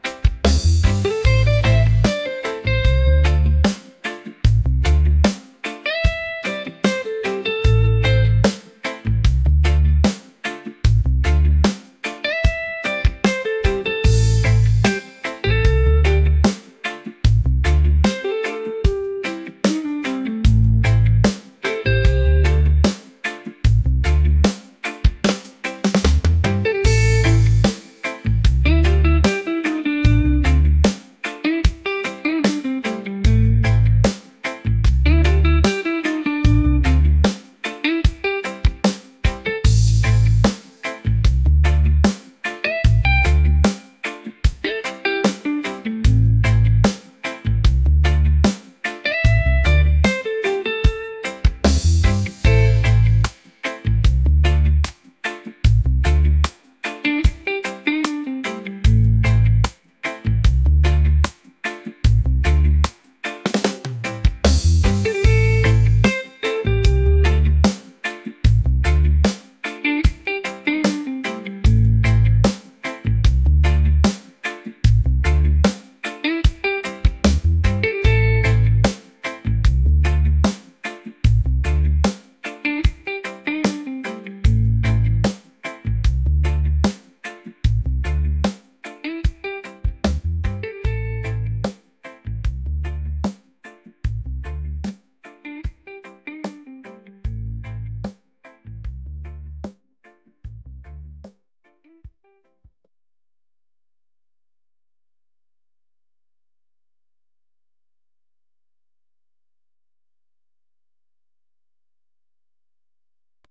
soulful | reggae